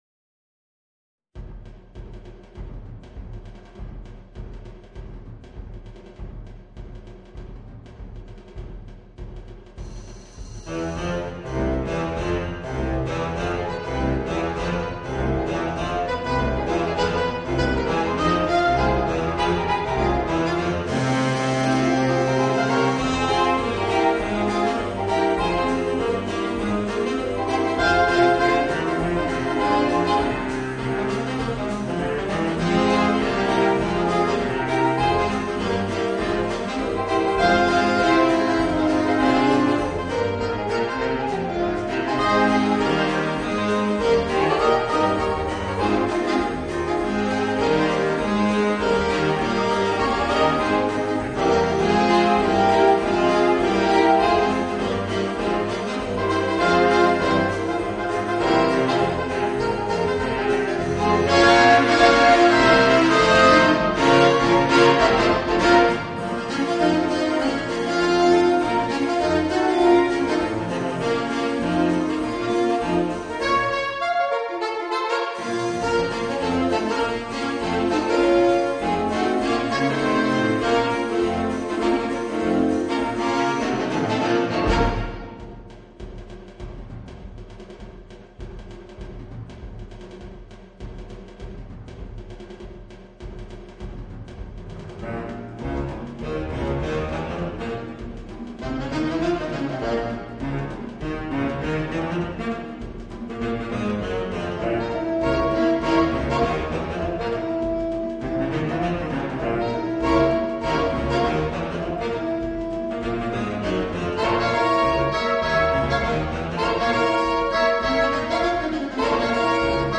für acht Saxophone